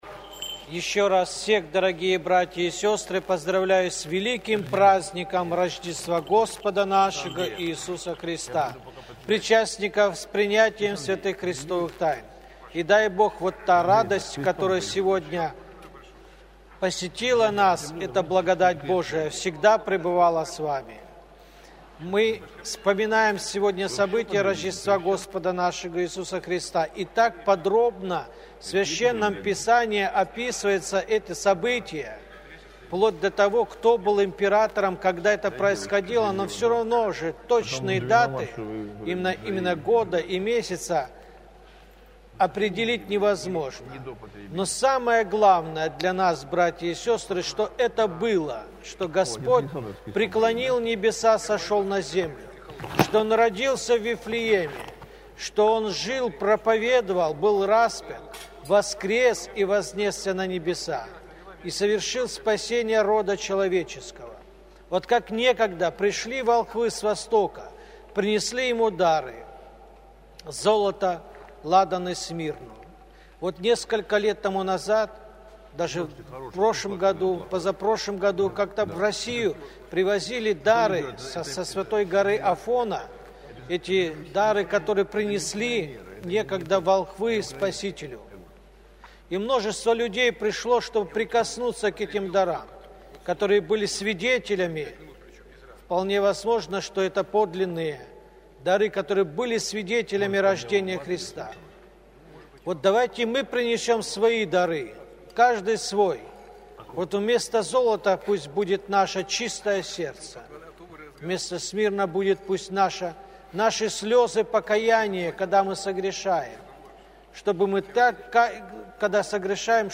Проповедь
Проповедь на Литургии читает епископ Балтийский Серафим